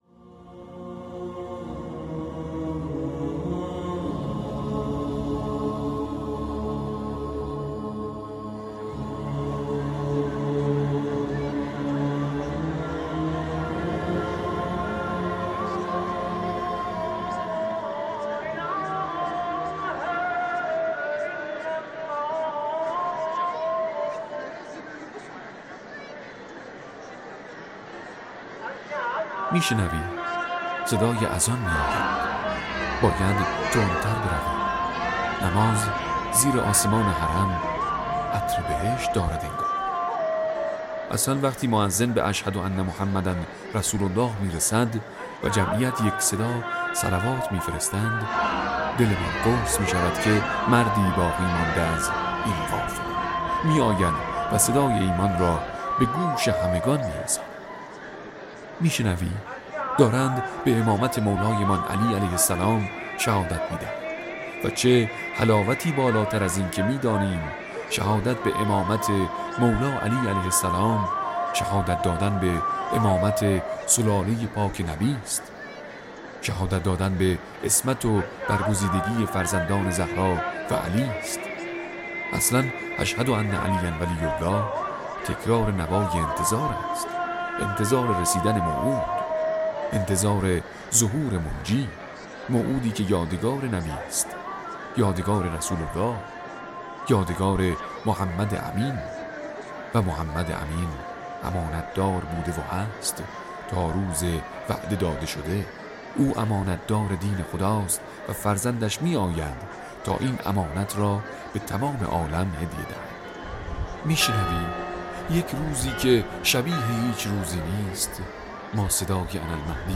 می‌شنوی؛ صدای اذان می‌آید، تندتر برویم نماز زیر آسمان حرم عطر بهشت دارد. خادمان رسانه‌ای امام رضا علیه‌السلام در پادکستی حال‌وهوای لحظات ناب اذان را در حرم مطهر به تصویر جان کشیده‌اند.